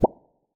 bubble_effect_04.wav